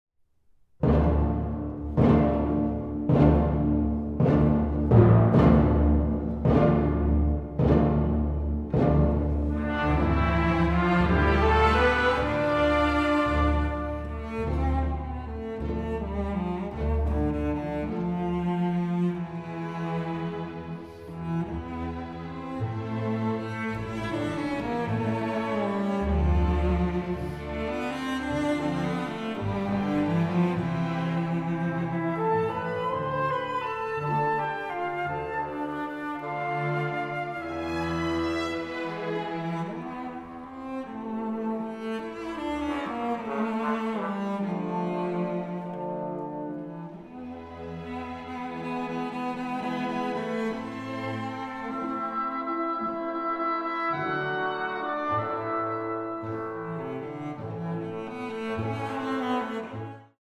Aufnahme: Rehearsal Hall, Megaron, Athens, 2024